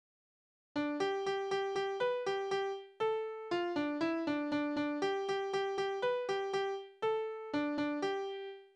Kinderlieder:
Tonart: G-Dur
Taktart: 2/4
Tonumfang: große Sexte
Besetzung: vokal